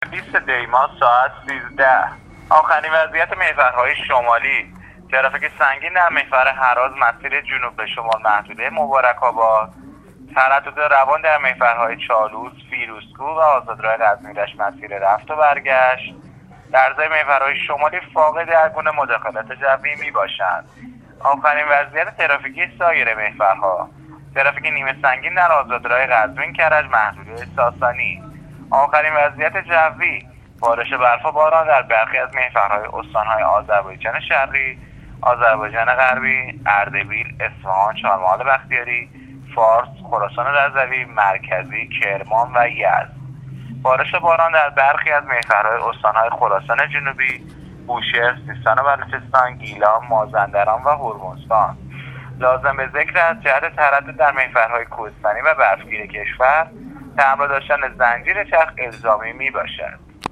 گزارش رادیواینترنتی از آخرین وضعیت‌ ترافیکی جاده‌ها تا ساعت۱۳ بیستم دی ۱۳۹۸